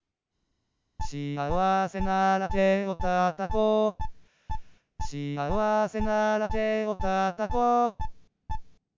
Singing Voice Synthesis
Below are some sample wav files of singing voice synthesized WITH and WITHOUT time-lag models:
without_01.wav